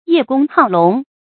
注音：ㄧㄜˋ ㄍㄨㄙ ㄏㄠˋ ㄌㄨㄙˊ
葉公好龍的讀法